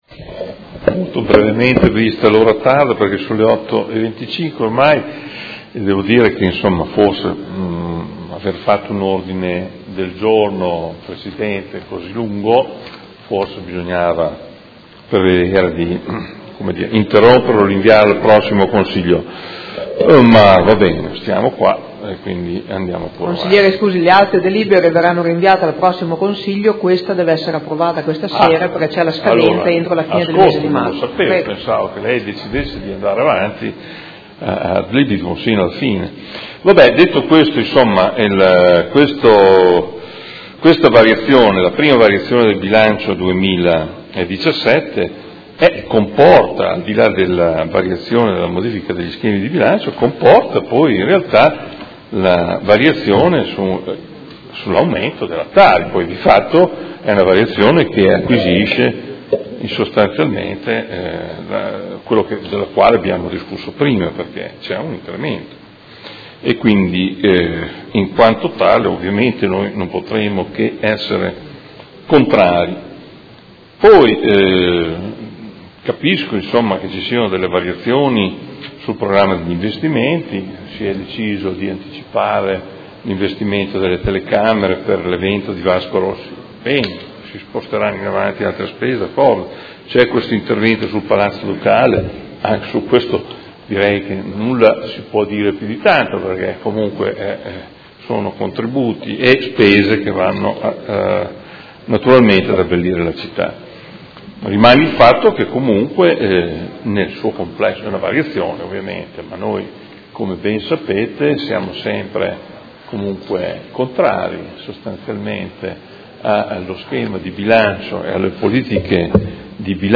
Dibattito su proposta di deliberazione: Bilancio triennale 2017-2019, Programma triennale dei lavori pubblici 2017-2019 – Variazione di Bilancio n. 1 – Riadozione di alcuni schemi di Bilancio